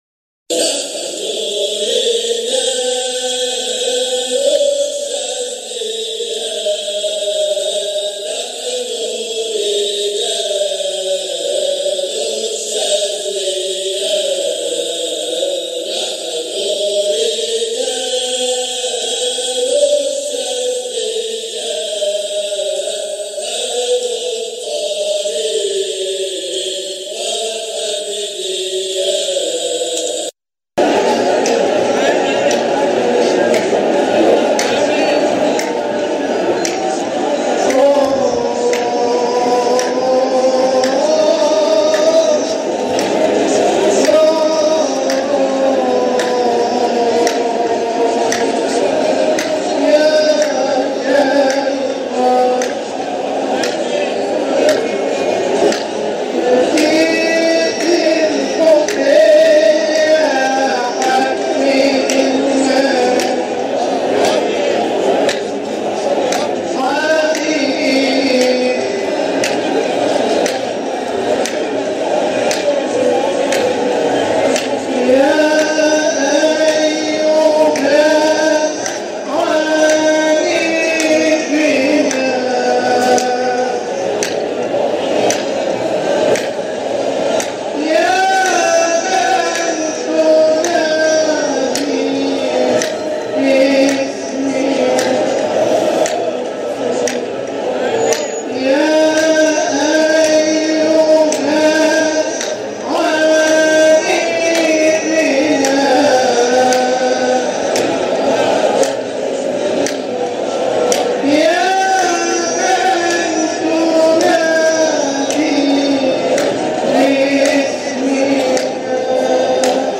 مقاطع من احتفالات ابناء الطريقة الحامدية الشاذلية بمناسباتهم
جزء من حلقة ذكر بمولد سيدنا احمد البدوى قُدس سره 2019